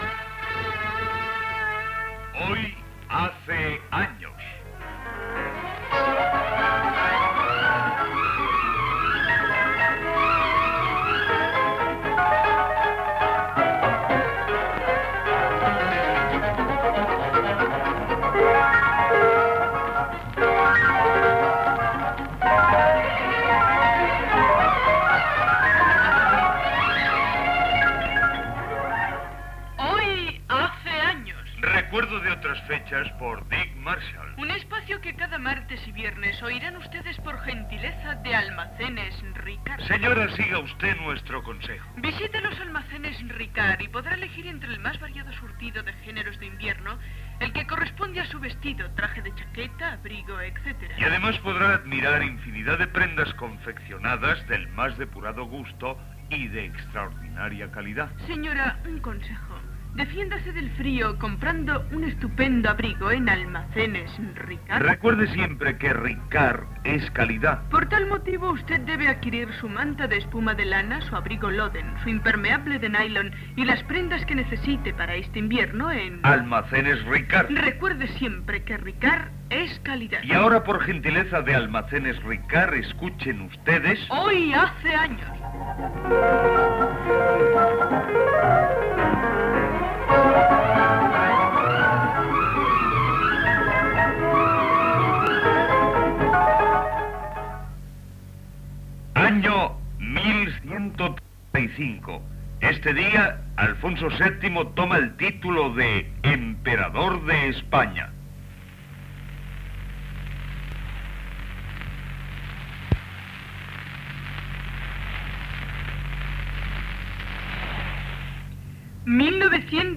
Efemèrides de la jornada amb publicitat: Alfonso VII Emperador d'Espanya, viatge de Colón, Vicente Espinel, Louis Braille , etc. Identificació de la ràdio, publicitat
Extret del programa dedicat al cinquentenari de Radio Panadés emès l'any 1982.